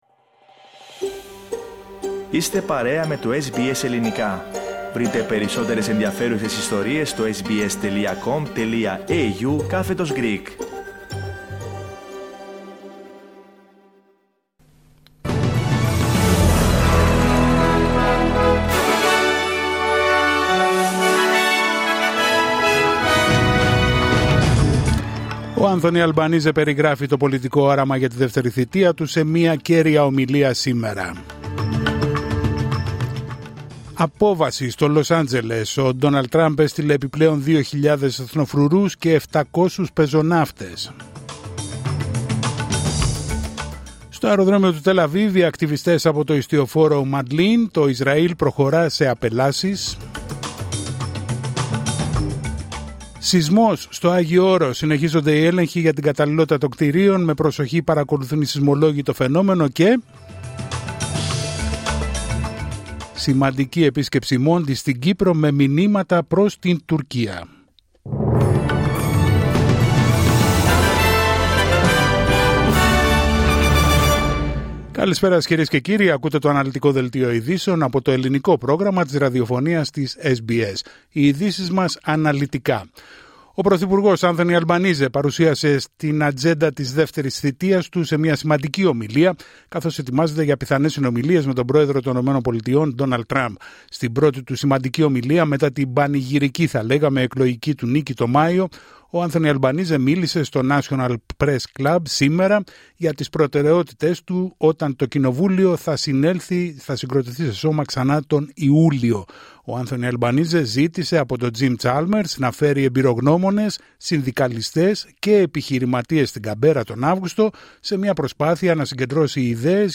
Δελτίο ειδήσεων Τρίτη 10 Ιουνίου 2025